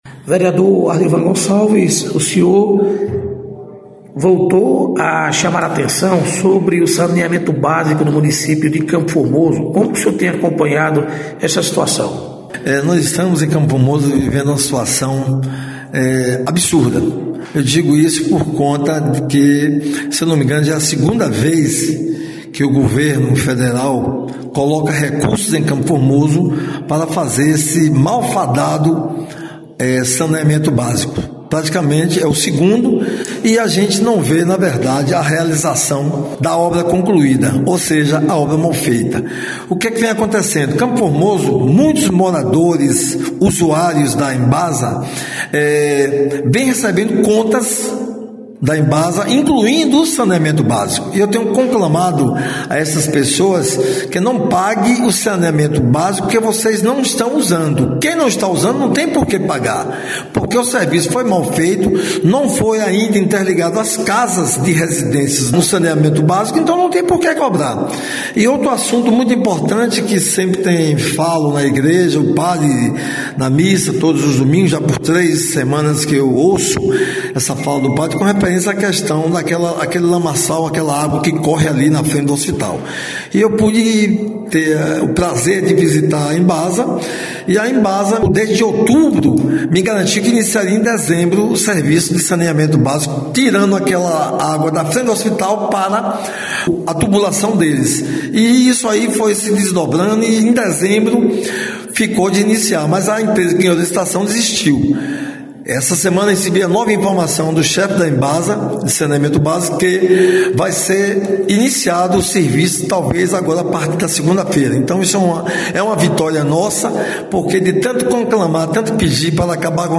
Reportagem: Vereadores do município de Campo Formoso